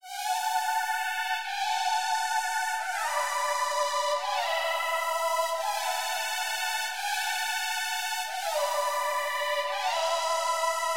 门 " 自助式门窗01
描述：对autoslidingdoors.aiff进行重新混音，使用降噪和压缩技术，使声音更干净，更细腻。
标签： 滑动 自动的 内部的 自动化的门 玻璃
声道立体声